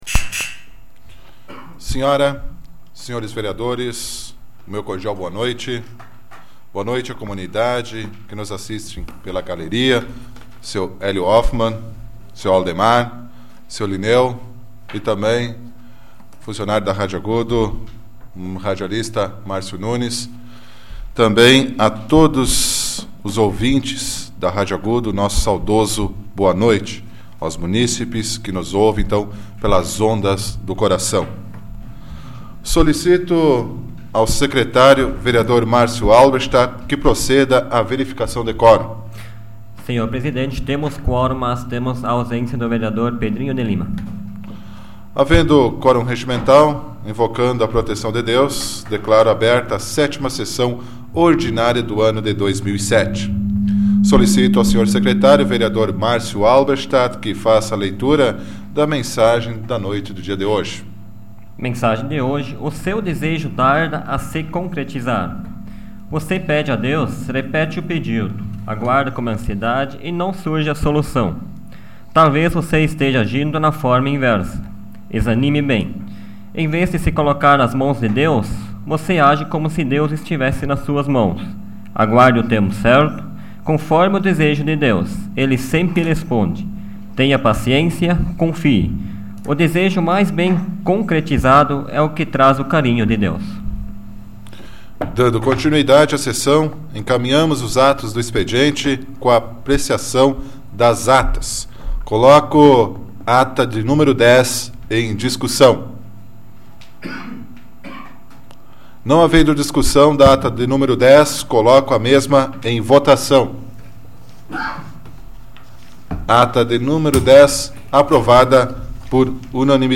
Áudio da 81ª Sessão Plenária Ordinária da 12ª Legislatura, de 16 de abril de 2007